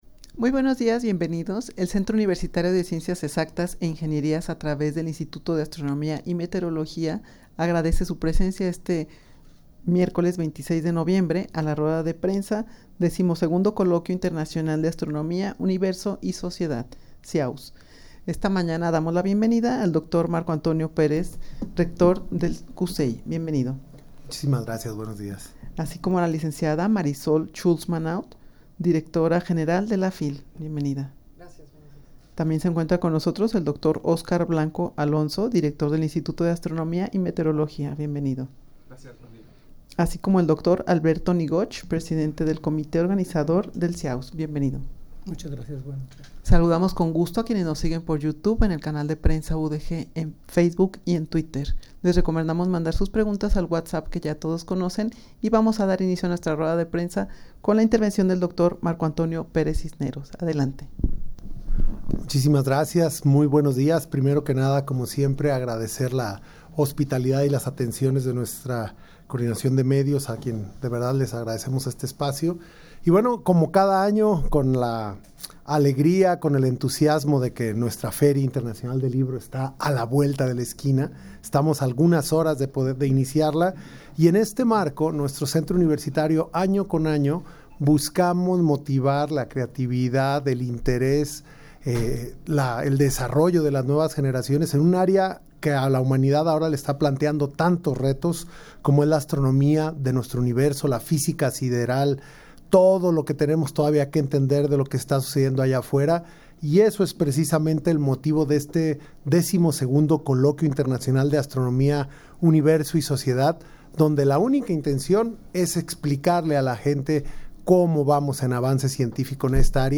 rueda-de-prensa-xii-coloquio-internacional-de-astronomia-universo-y-sociedad.mp3